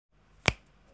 snap.ogg